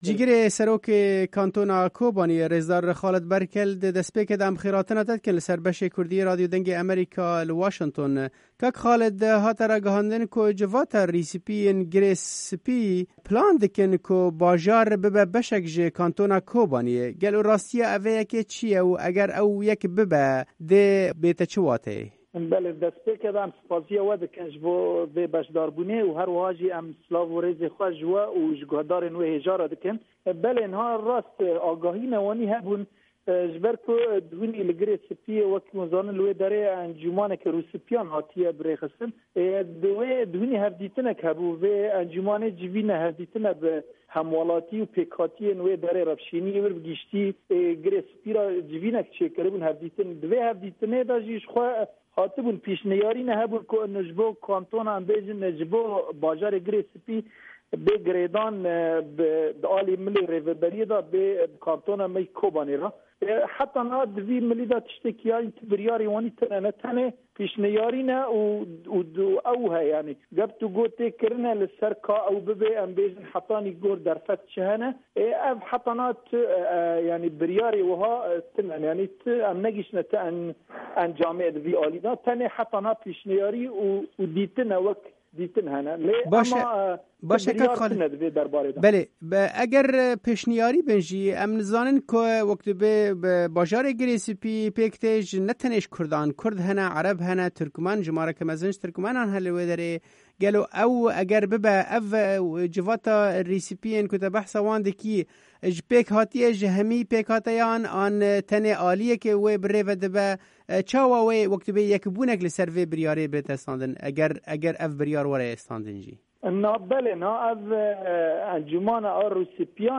Cîgirê serokê Kantona Kobaniyê rêzdar Xalid Berkel ji Dengê Amerîka re ronî da ser vê babetê û got: